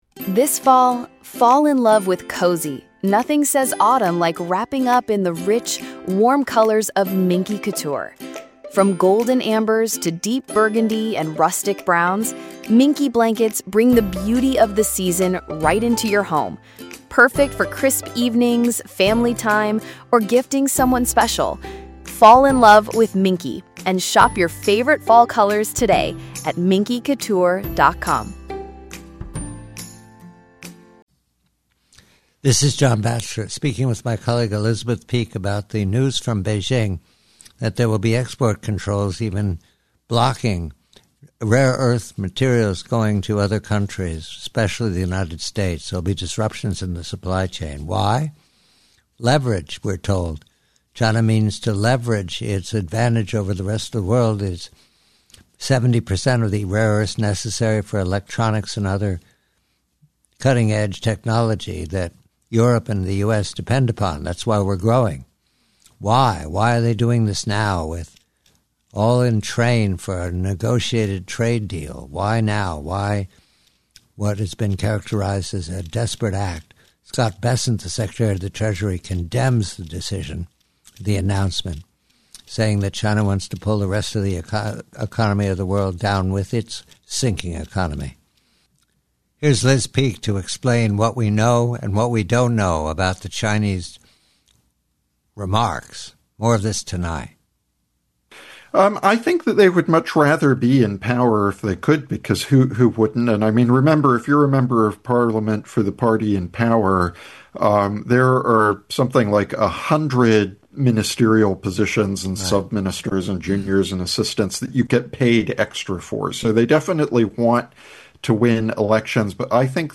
John Batchelor speaks with Elizabeth Peek about Beijing's decision to implement export controls, potentially blocking rare earth materials essential for US and European electronics. China leverages its 70% global share, causing supply chain disruptions.